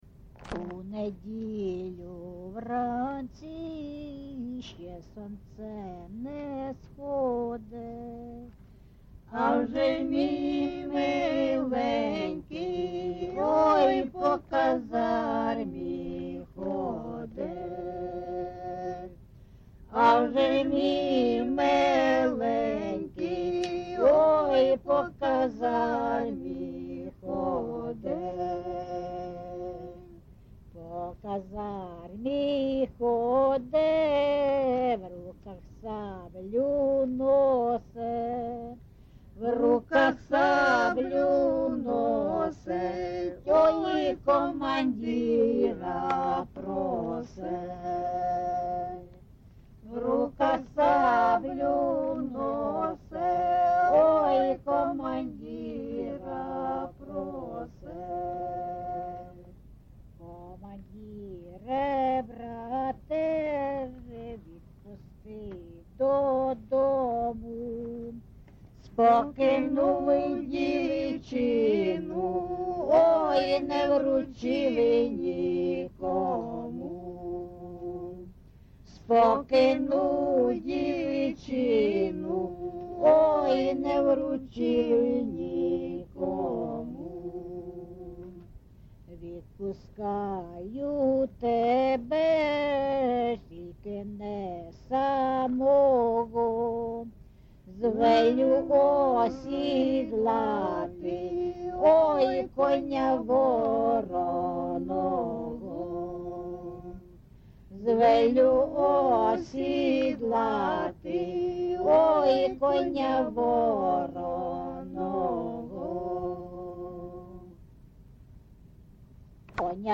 ЖанрКозацькі, Солдатські
Місце записус. Андріївка, Великоновосілківський район, Донецька обл., Україна, Слобожанщина